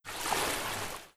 WaterSound.wav